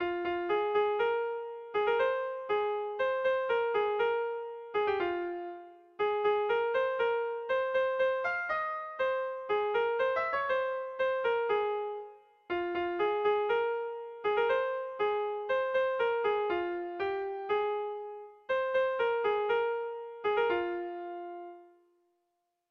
Erromantzea
Seikoa, ertainaren moldekoa, 3 puntuz (hg) / Hiru puntukoa, ertainaren moldekoa (ip)
ABA2